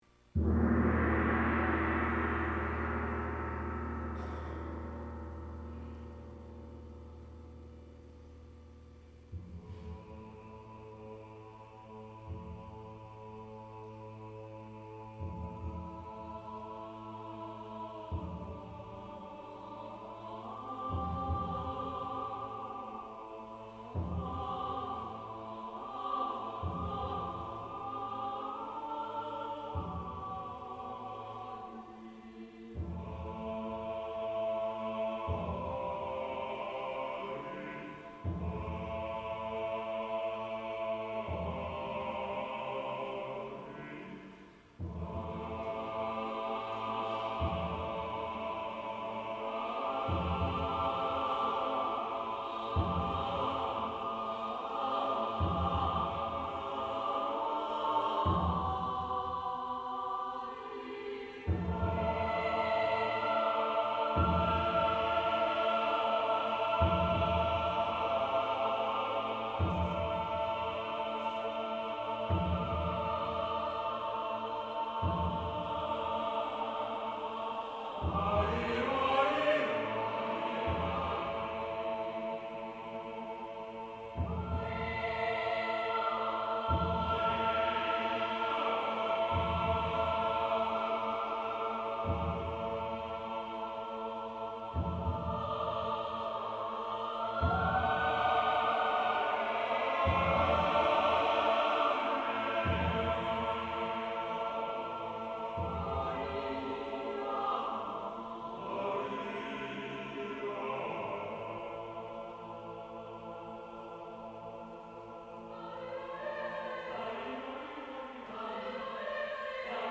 Voicing: 3xSATB/per